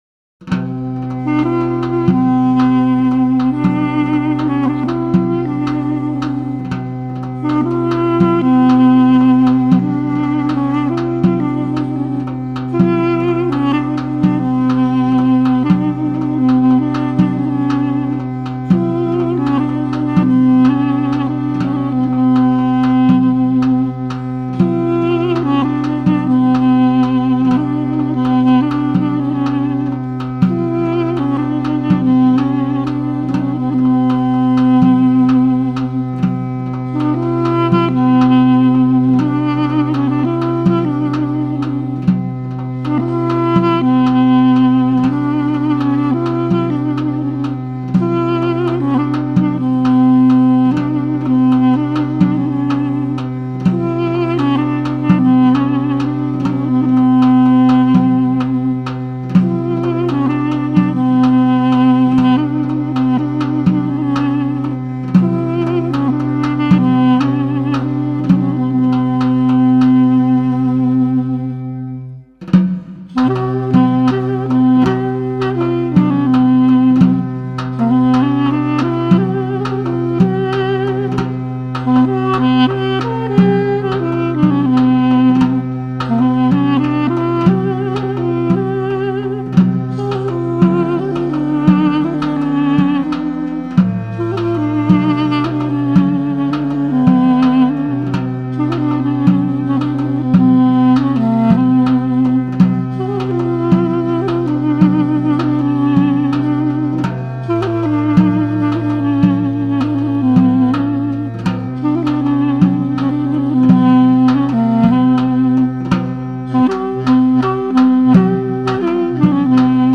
The warm, nasal tones of the duduk played by
There is probably no other woodwind instrument
with a softer sound, and the folk music of
his fellow musicians with tangible emotion.